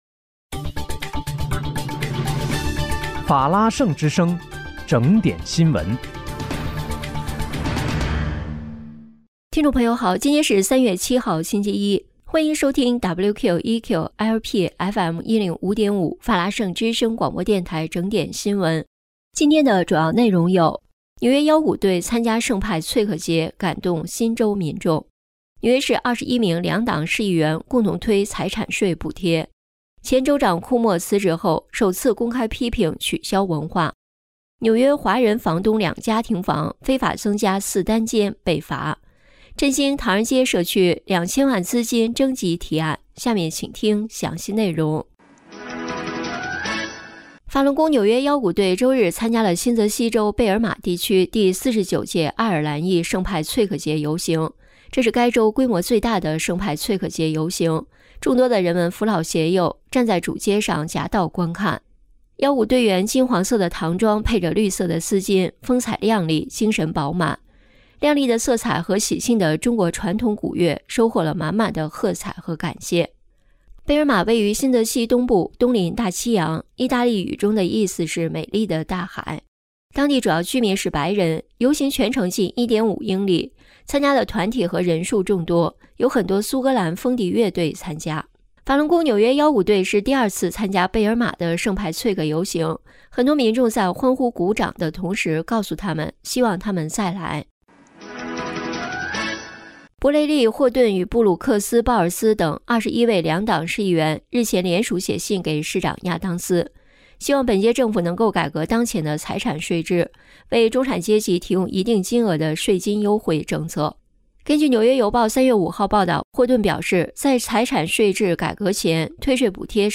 3月7日（星期一）纽约整点新闻